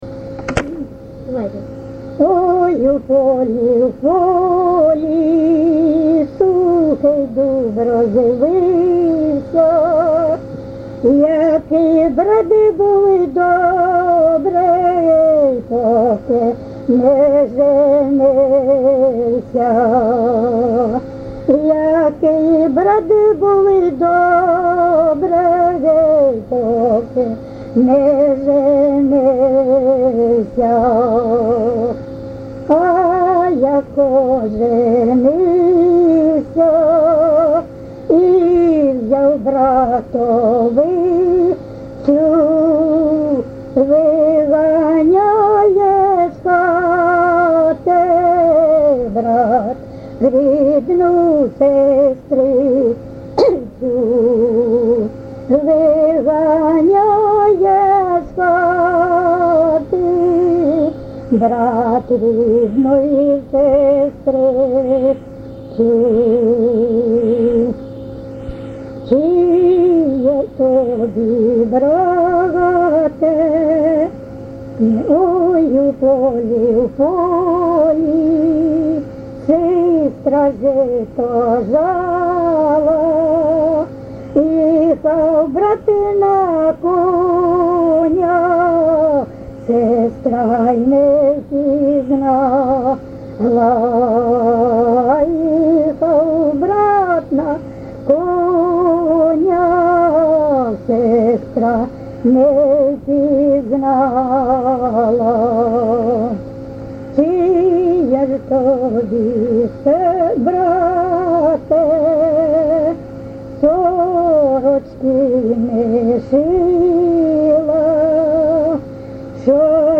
ЖанрПісні з особистого та родинного життя
Місце записус. Коржі, Роменський район, Сумська обл., Україна, Слобожанщина